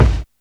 kits/RZA/Kicks/WTC_kYk (77).wav at main